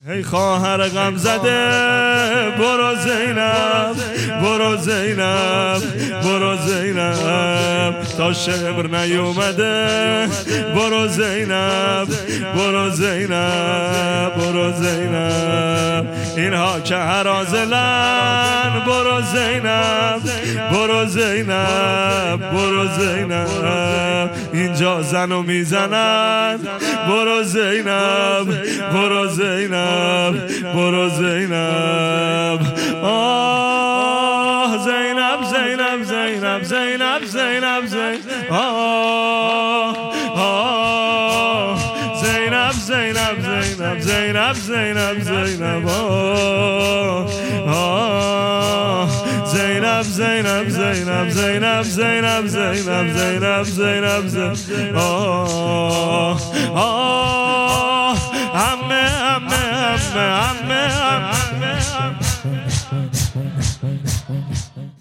شب شهادت امام کاظم علیه السلام